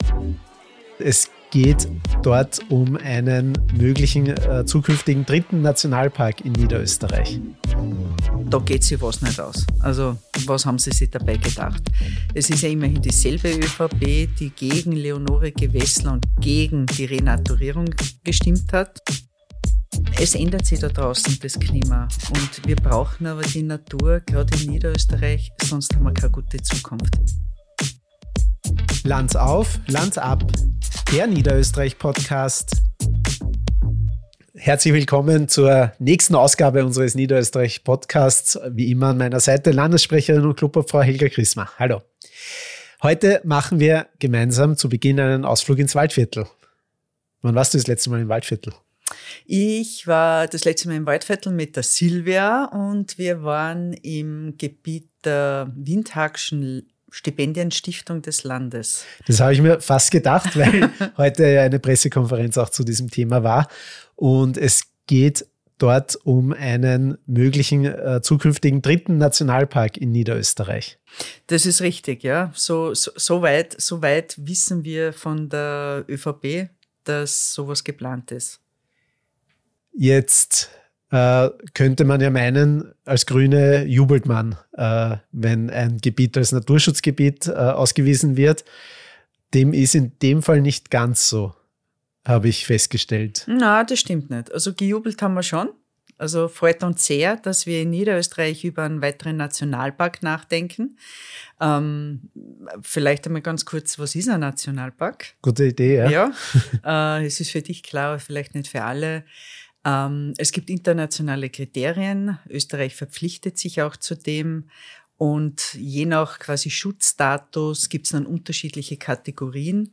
Die beiden Abgeordneten analysieren, warum private Forstwirte sich ungerecht behandelt fühlen, wie Natura 2000 in Niederösterreich nur halbherzig umgesetzt wird und was es braucht, damit aus der Idee ein echter Nationalpark mit Mehrwert für Mensch und Natur entstehen kann: transparente Kommunikation, Vertrauen und den Mut, über die Landesstiftung hinaus zu denken.